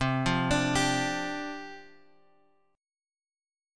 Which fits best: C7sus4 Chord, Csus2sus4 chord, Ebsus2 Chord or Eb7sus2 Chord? Csus2sus4 chord